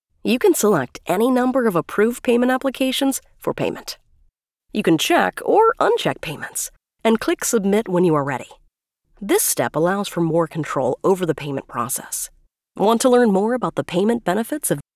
English (American)
Commercial, Young, Natural, Friendly, Corporate
Corporate
Young adult, female voice, ages 25 to 45. North American, English speaker. Conversational, authentic, and believable voice.